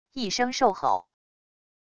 一声兽吼wav音频